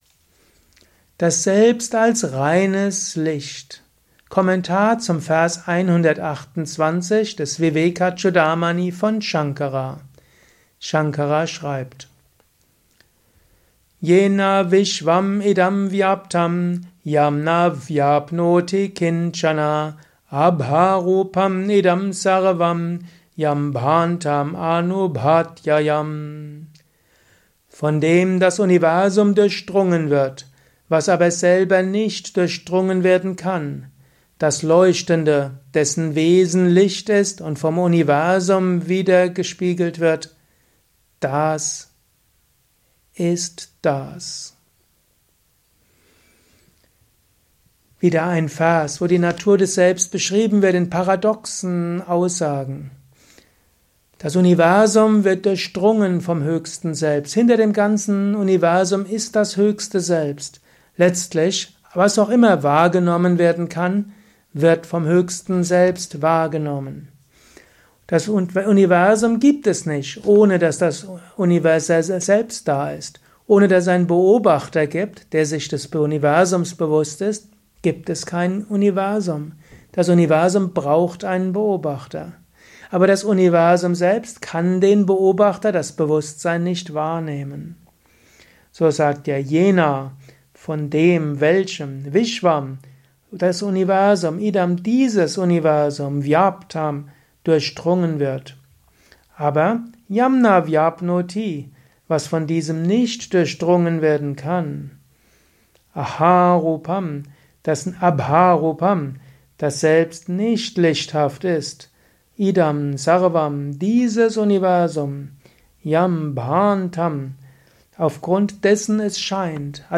Lausche in dieser Ausgabe des Täglichen-Inspirationen-Podcast dem Kommentar